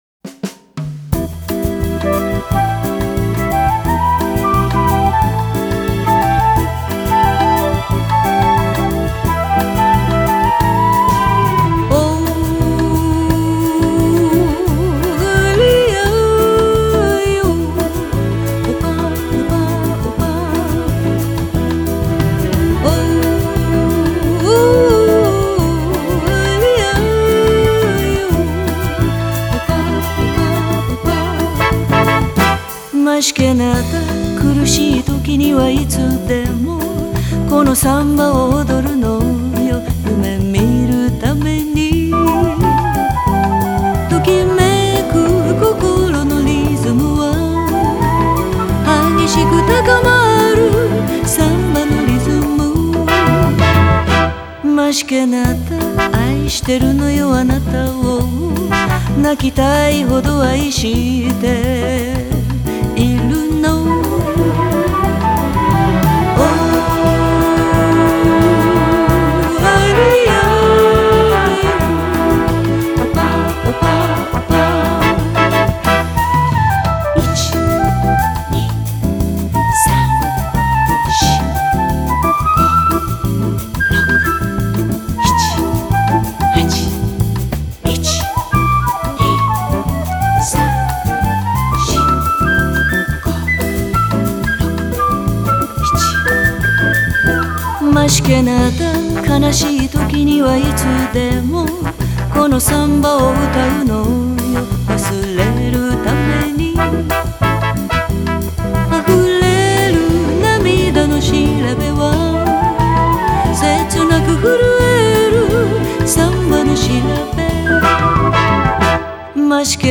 ジャンル: Jazz